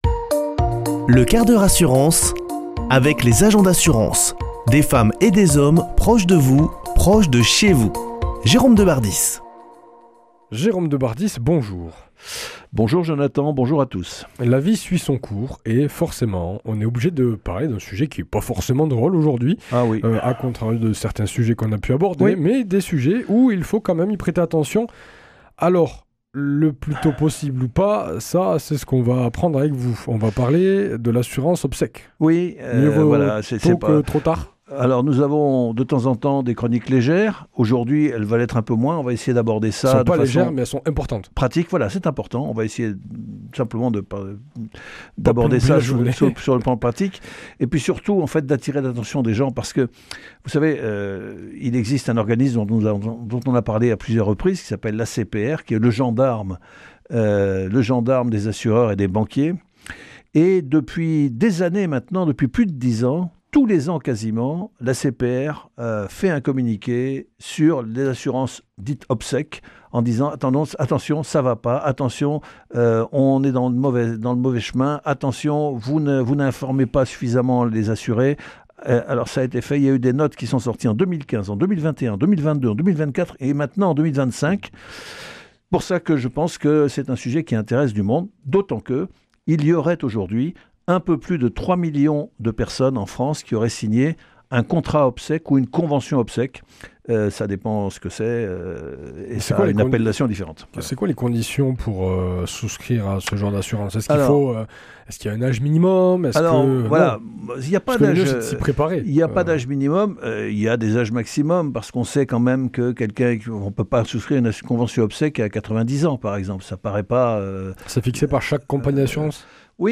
Chroniqueur